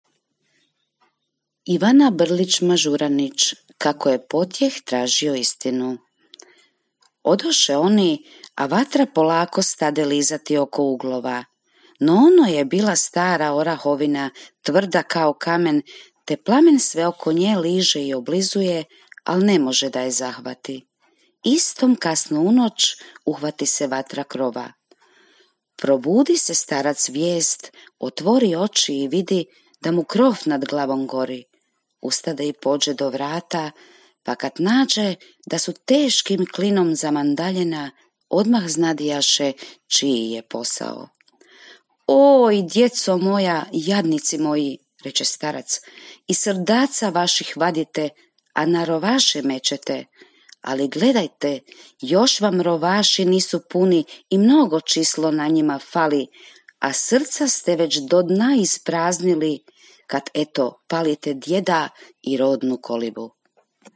Jedan od načina obilježavanja Mjeseca hrvatske knjige je i Minuta za čitanje – akcija čitanja naglas.